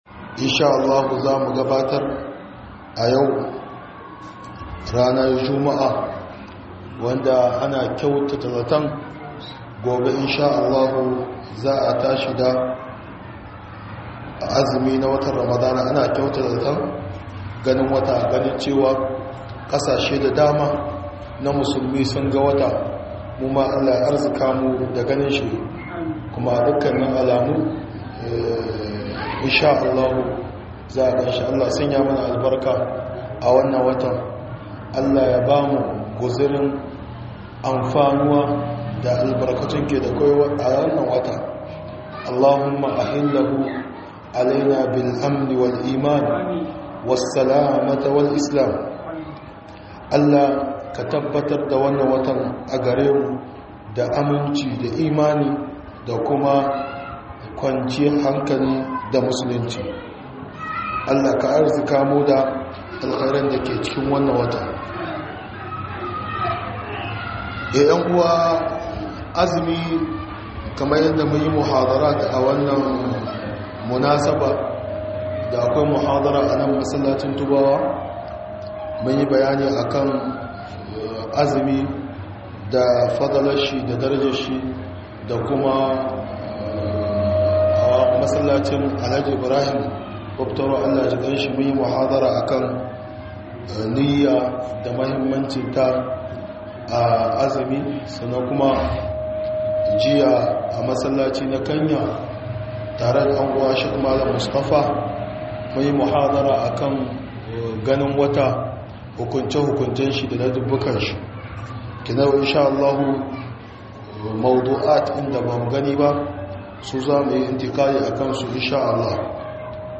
005-Wasu hukunce hukuncen azumi Ramadan da ladubban sa - MUHADARA